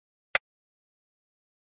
Вы найдете различные варианты щелчков и клацанья выключателя: от классических резких до современных мягких.
Щелчок выключателя при выключении света